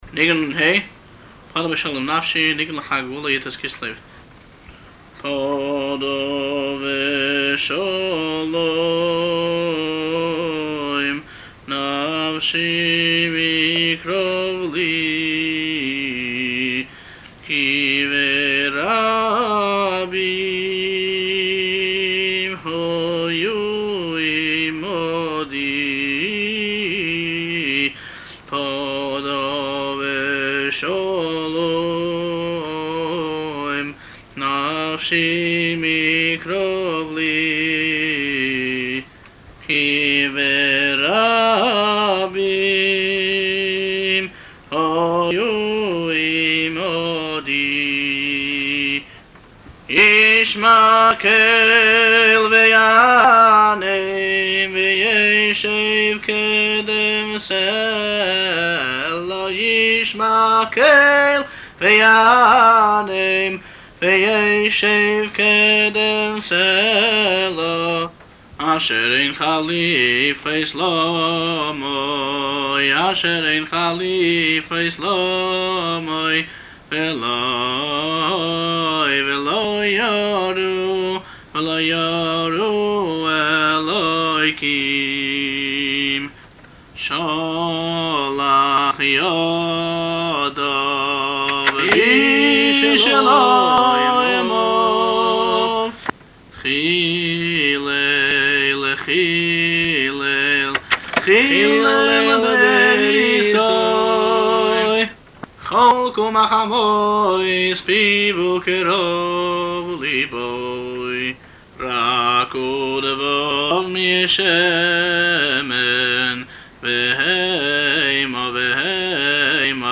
לשמיעת הניגון מאת הבעל-מנגן